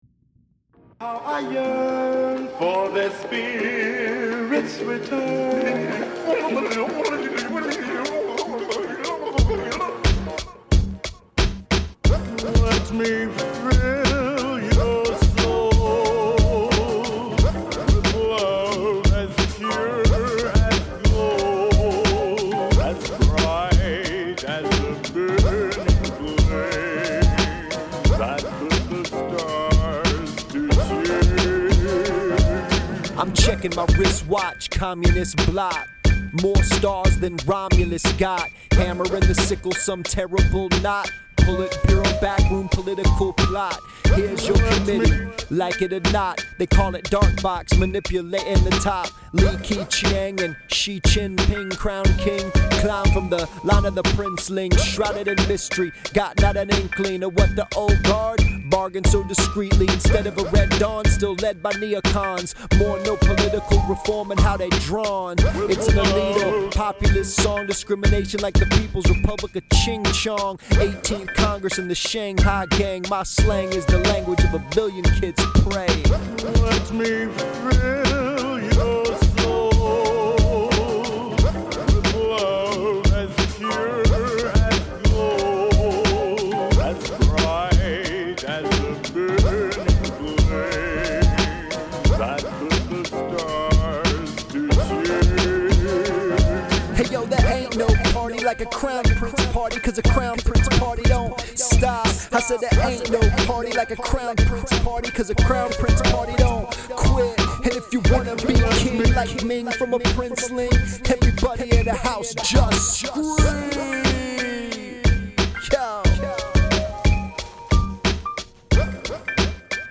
But, hey, that didn’t stop me from writing a whole news rap about it, like an expert.
By the way, it’s 2:21 am and I’m recording this, with the worst mic I have, in the front seat of my Volvo (in honor of the Cullens of course).